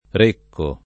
Recco [ r % kko ]